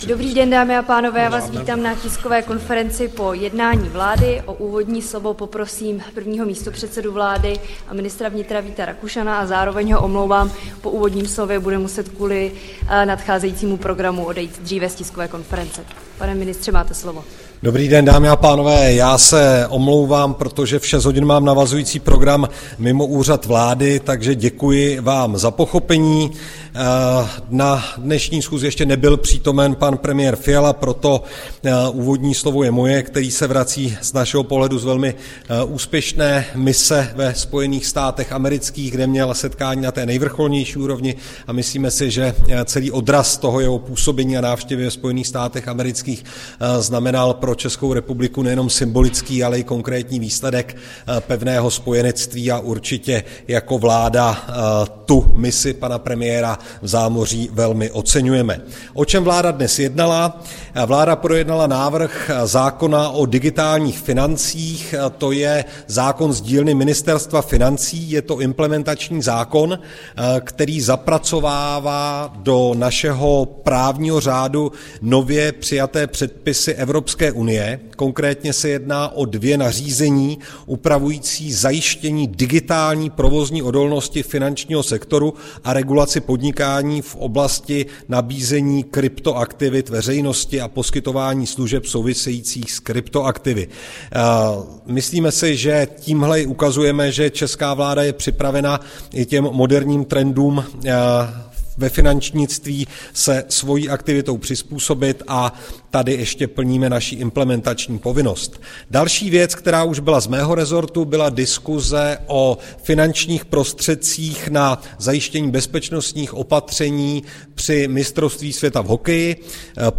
Tisková konference po jednání vlády, 17. dubna 2024